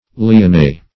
Meaning of lyonnais. lyonnais synonyms, pronunciation, spelling and more from Free Dictionary.